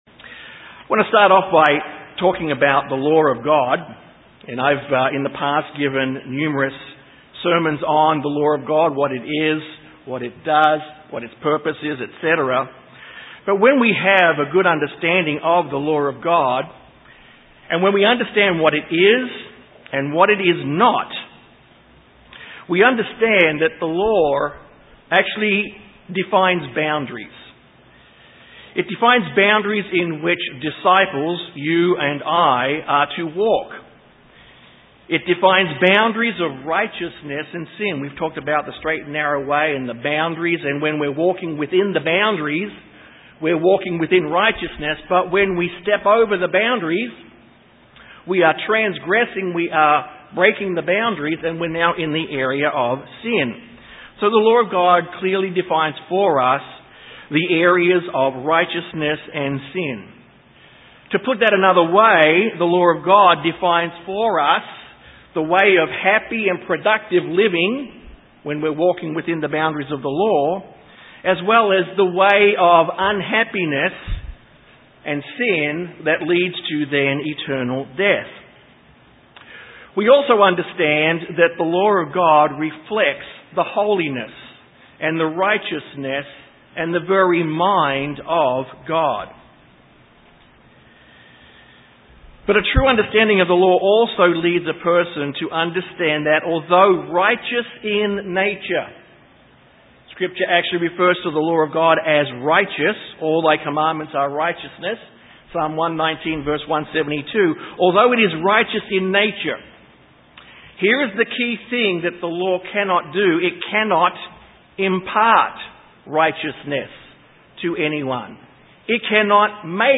Sermons
Given in San Antonio, TX